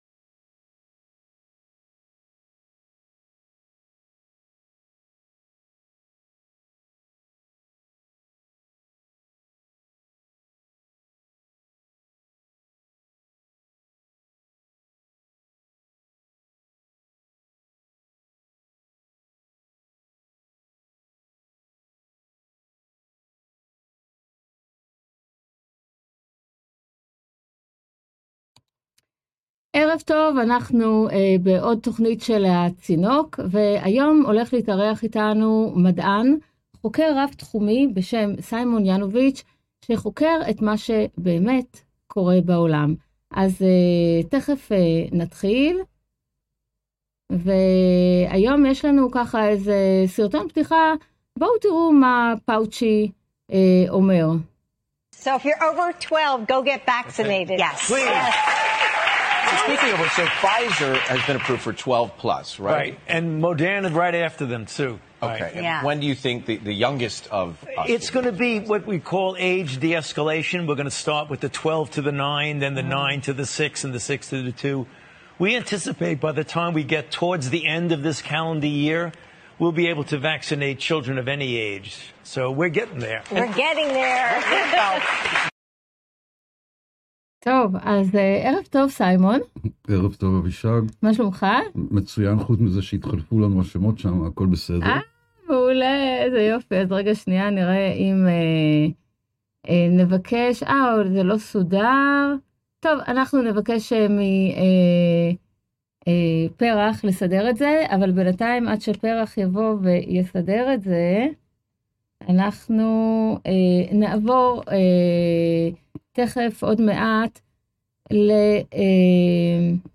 אורח באולפן